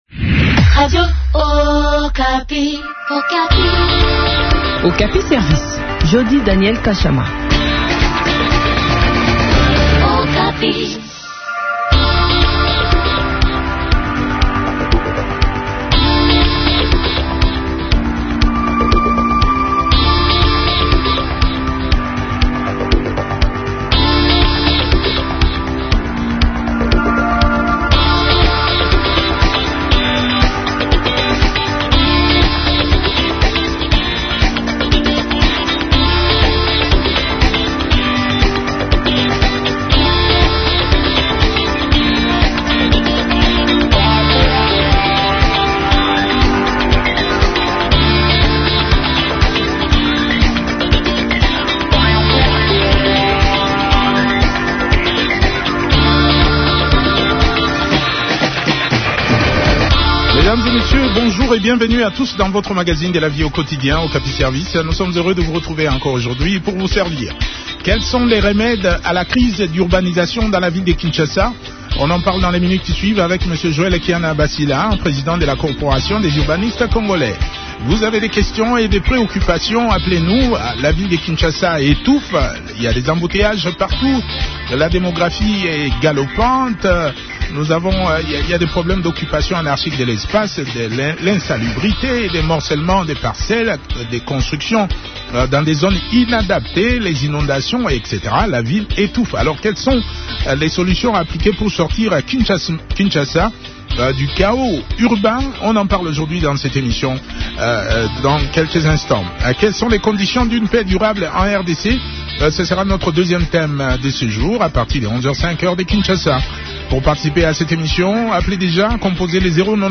architecte, donne des pistes de solutions dans ces échanges